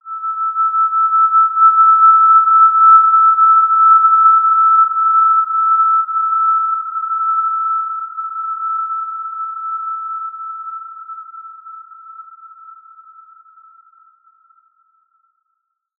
Basic-Tone-E6-mf.wav